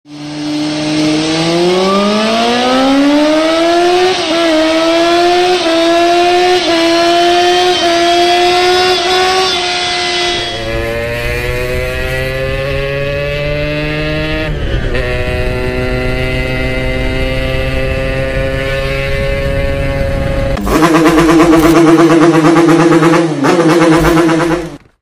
Suara Motor ngebut
Kategori: Suara Kendaraan
Keterangan: Suara Motor Ngebut adalah suara khas motor yang dipacu dengan kecepatan tinggi, menghasilkan bunyi deru mesin yang bertenaga dan memacu adrenalin.
suara-motor-ngebut-www_tiengdong_com.mp3